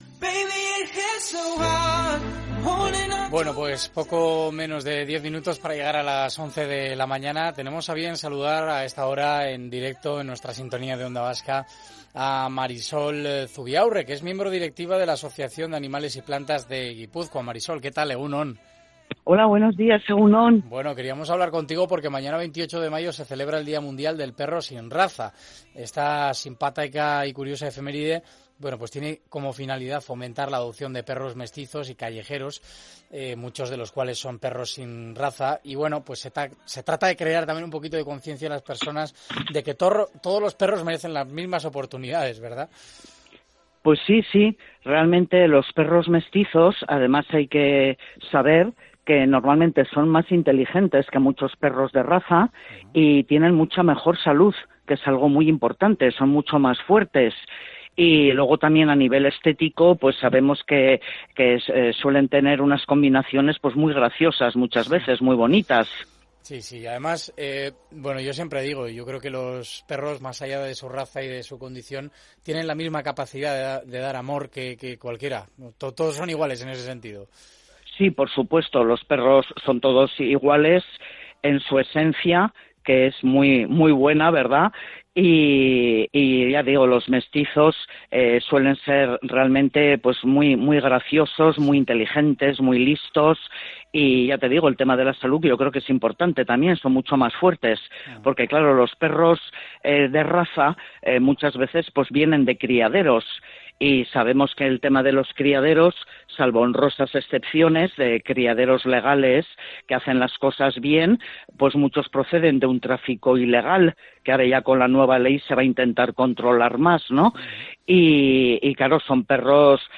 Aprovechando la ocasión, ayer tuvimos la oportunidad de charlar con la directiva de la Asociación de Animales y Plantas de Gipuzkoa